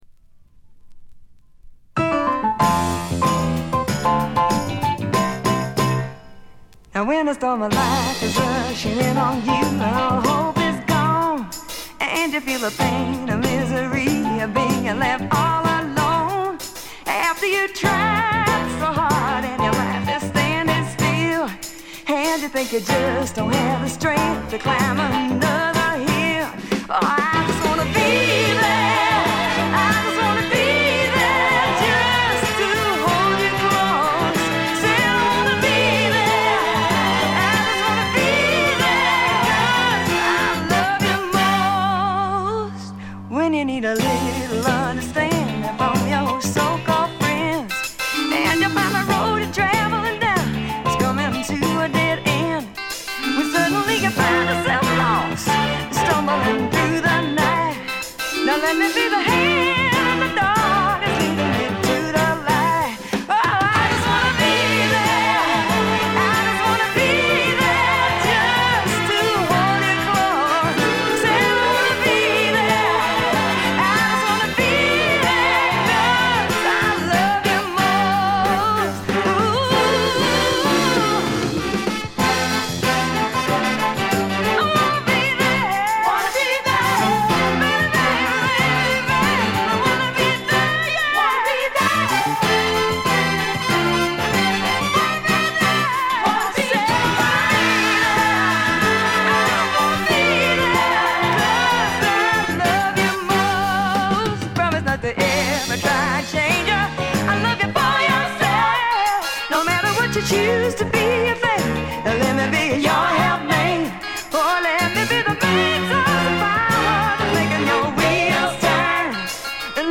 大きなノイズはありません。
フリー・ソウル系のレアグルーヴものとしても高人気の一枚です。
試聴曲は現品からの取り込み音源です。